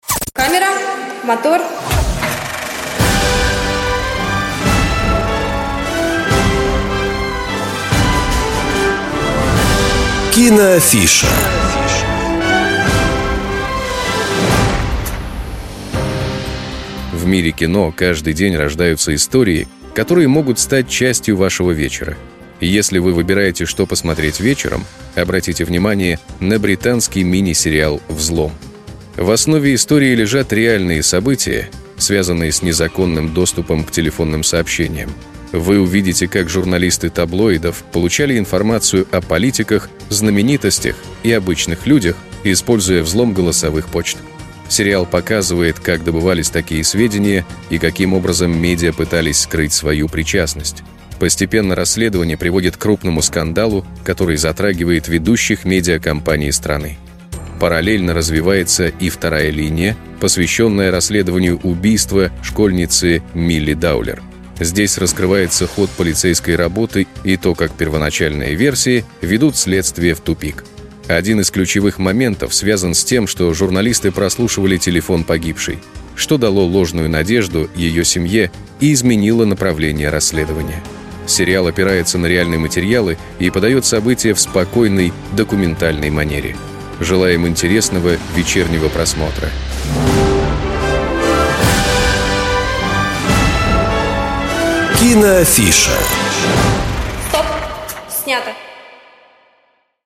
Небольшие аудиорассказы о фильмах и сериалах, которые помогут определиться с выбором на вечер.